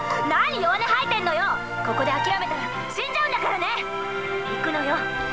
Listen to Megumi as Musashi